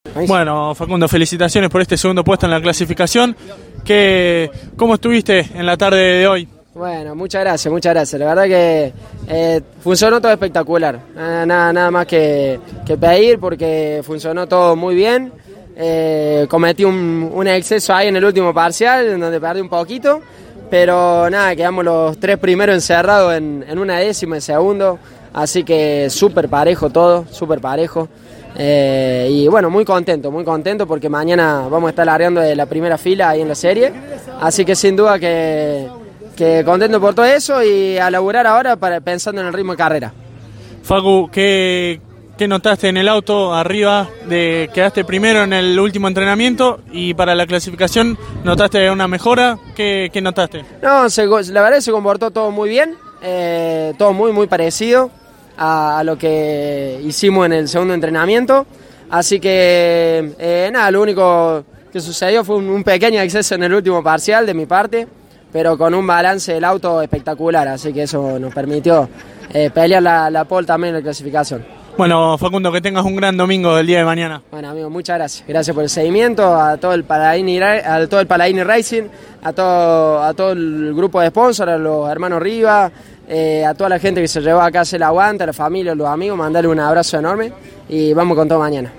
Por último, los tres protagonistas más importantes de la clasificación dialogaron con CÓRDOBA COMPETICIÓN y estos son sus testimonios: